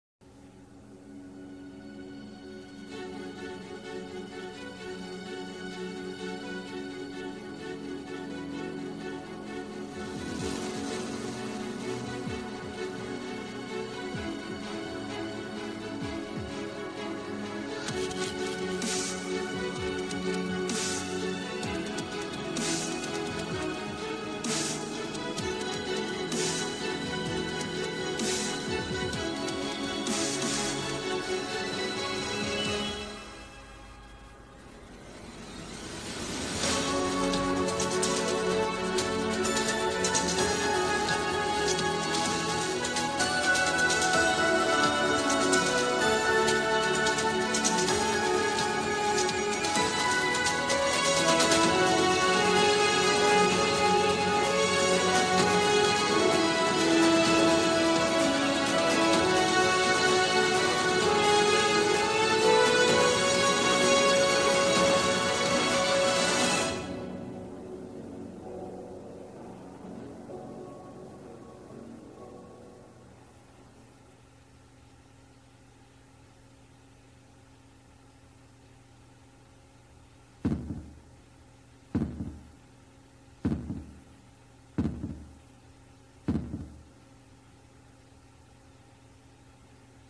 映画『矛盾する者』告知風声劇